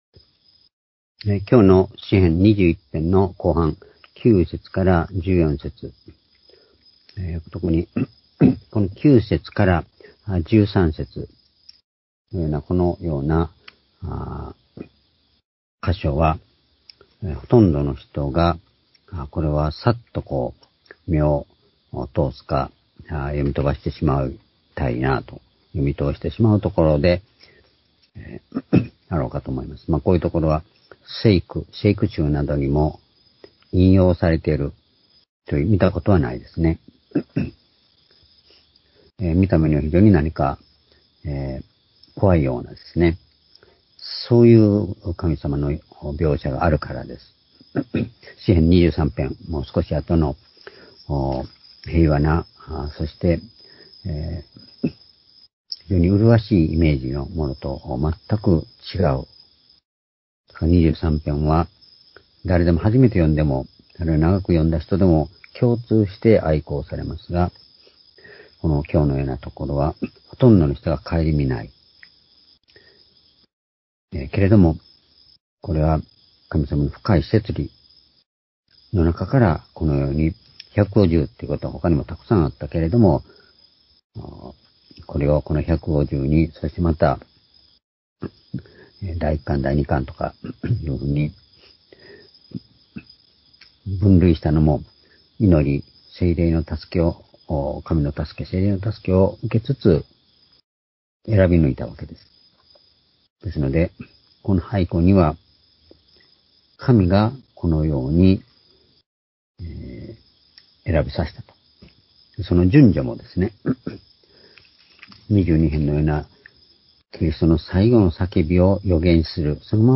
（主日・夕拝）礼拝日時 2021年9月7日（夕拝） 聖書講話箇所 「悪の力にうち勝つ主」 詩編21編9節～13節 ※視聴できない場合は をクリックしてください。